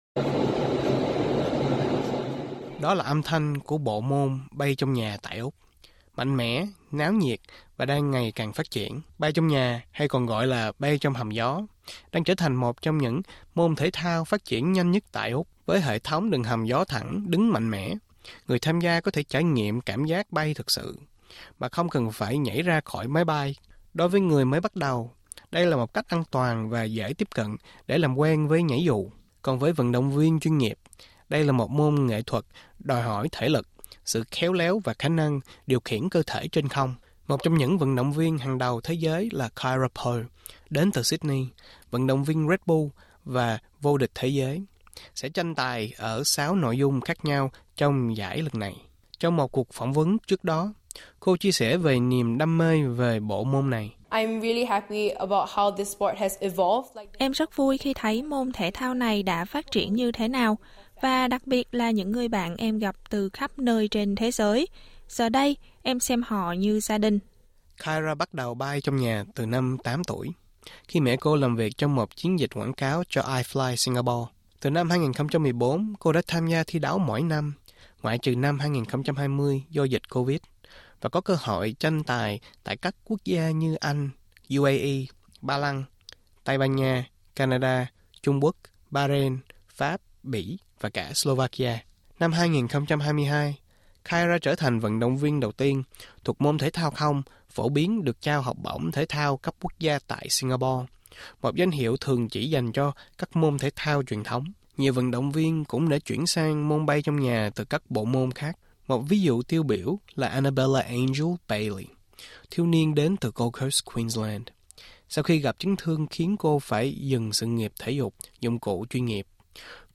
Đó là âm thanh của bộ môn bay trong nhà tại Úc — mạnh mẽ, náo nhiệt và đang ngày càng phát triển.